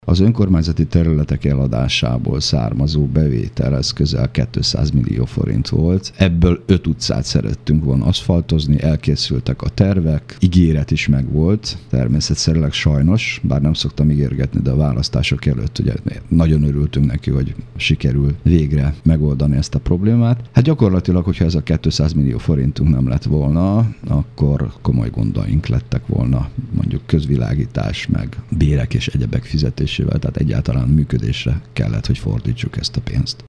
Most azonban a működésre kellett fordítaniuk a pénzt - mondta dr. Gál Imre polgármester.